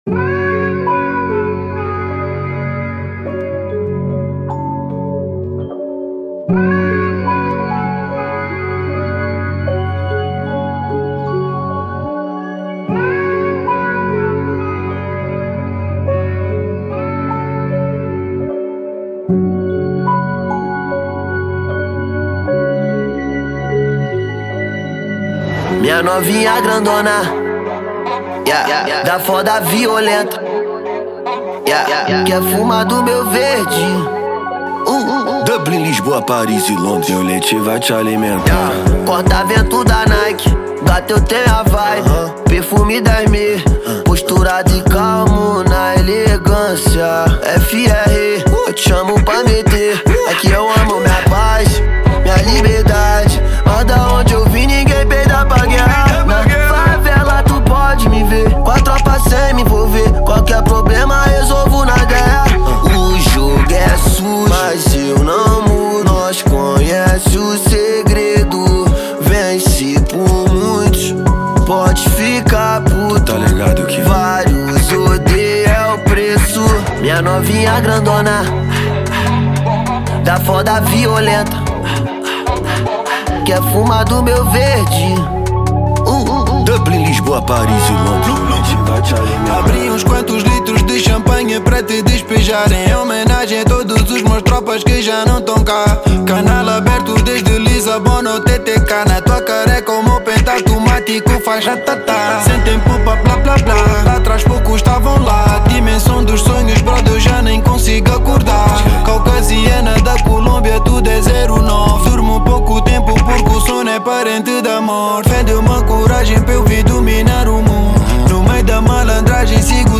Genero: Drill